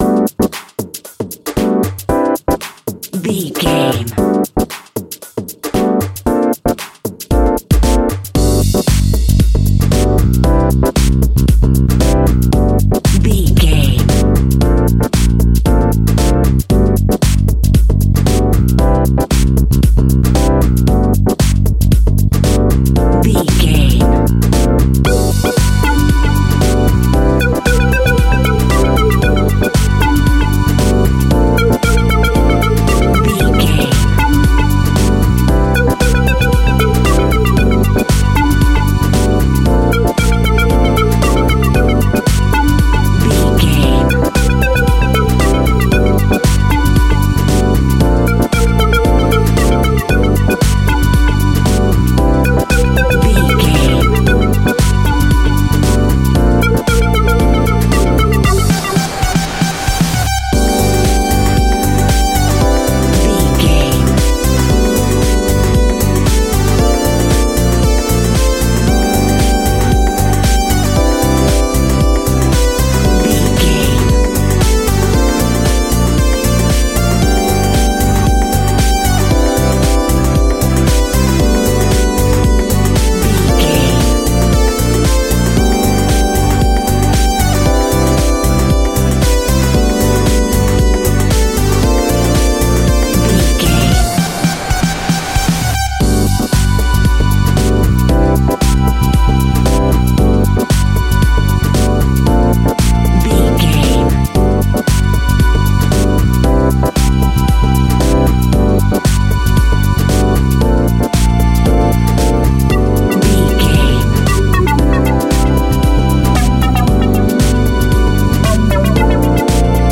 Funk House Music Sounds.
Ionian/Major
groovy
uplifting
driving
energetic
drums
synthesiser
electric piano
instrumentals